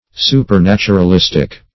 Supernaturalistic \Su`per*nat`u*ral*is"tic\, a.